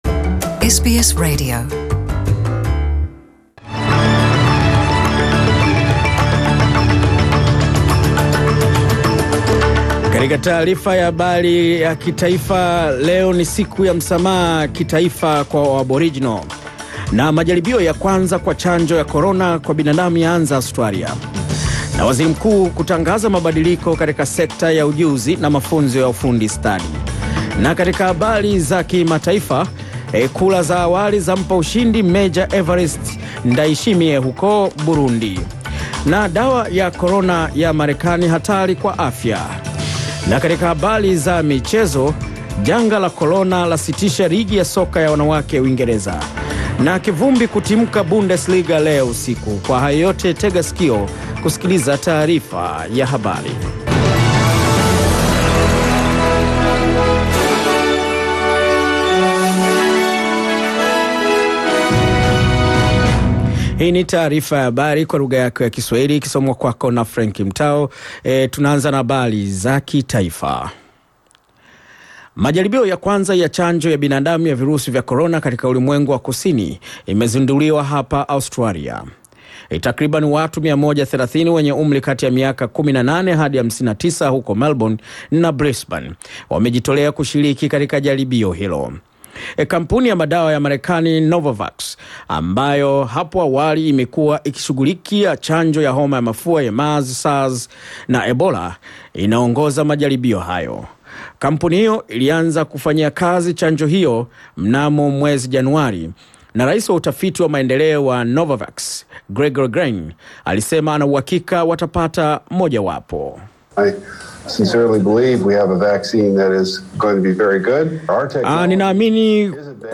Taarifa ya Habari 26 Mei 2020